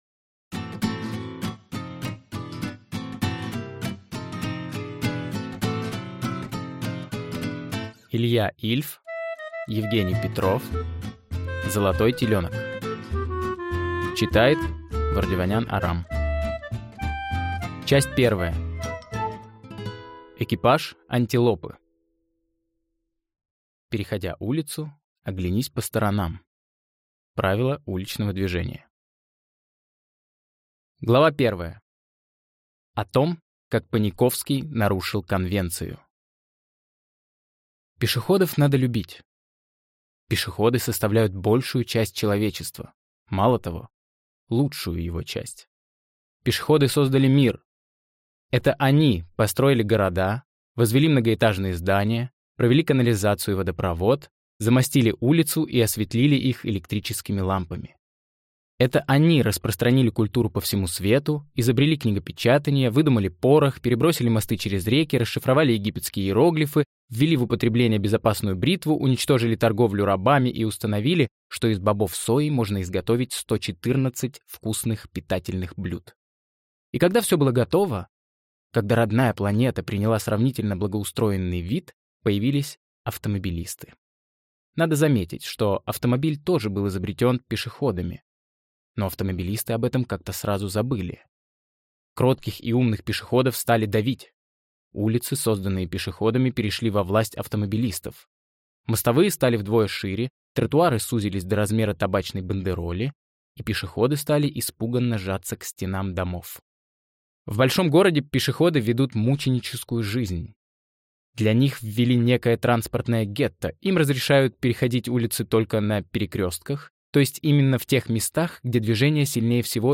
Аудиокнига Золотой теленок | Библиотека аудиокниг